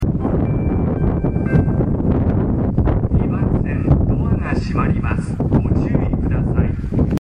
音質はとてもいい です。
発車メロディーフルコーラスです。